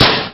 gastank.ogg